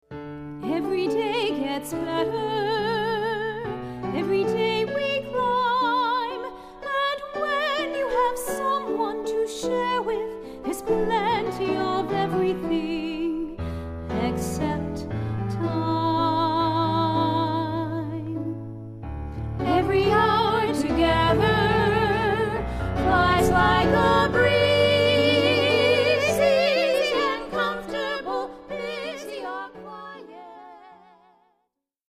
Stylish songs and standup comedy expose the foibles, relationships, worries, insights, passions and increasingly frequent memory lapses that characterize this rich phase of life